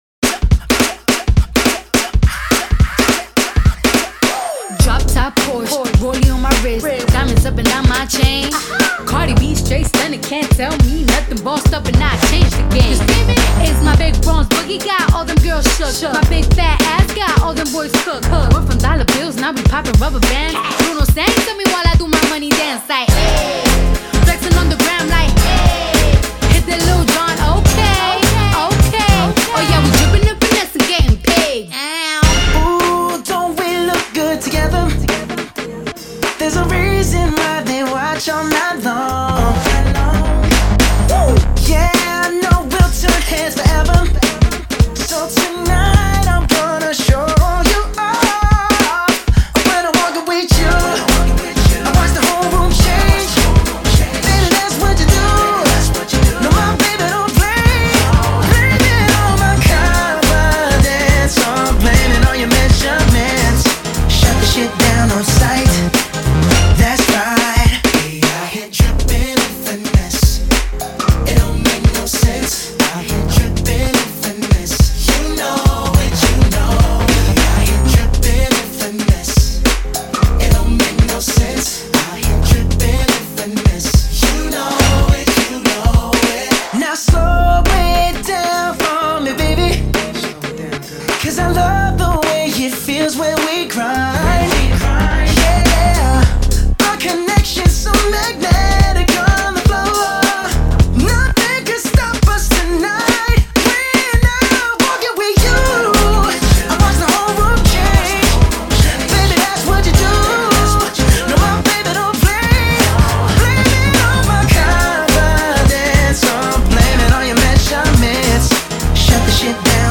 это яркий и энергичный трек в жанре R&B и хип-хоп